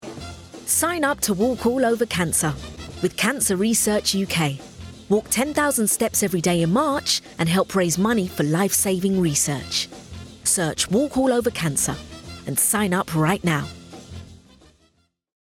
30/40's London, Likeable/Upbeat/Confident